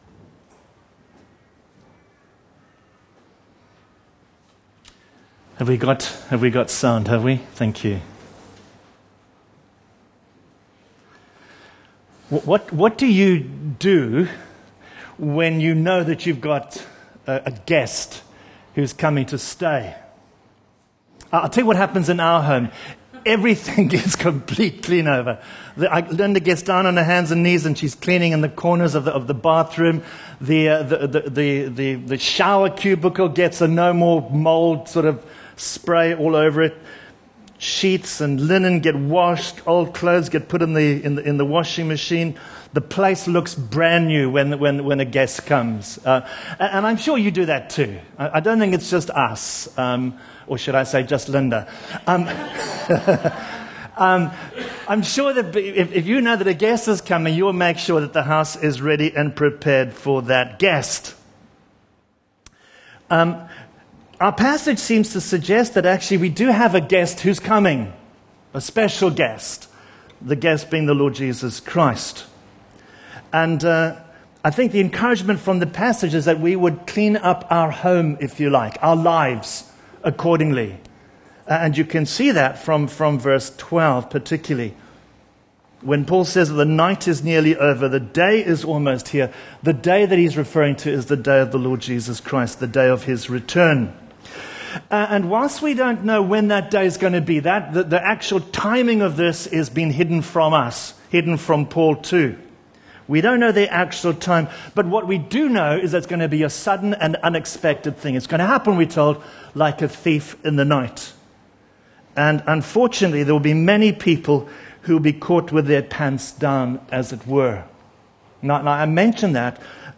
Bible Talks Bible Reading: Romans 13:8-14